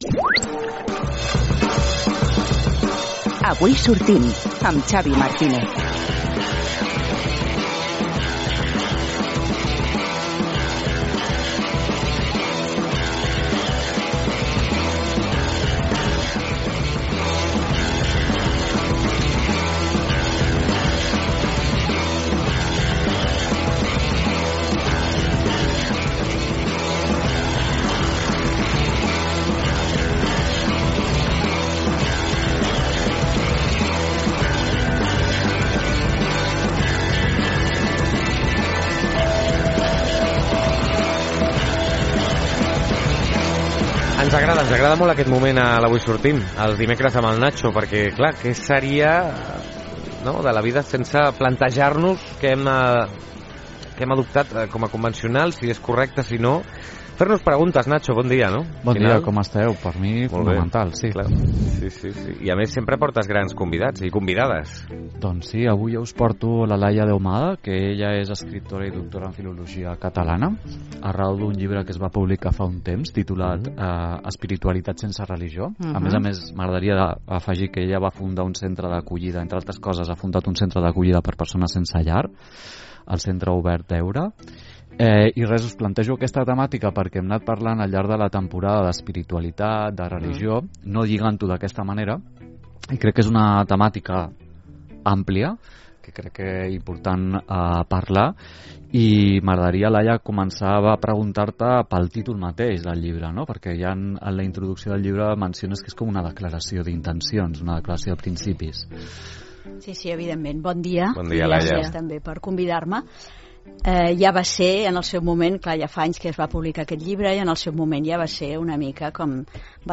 10 abr. 2016 — Centre Cultural Terrassa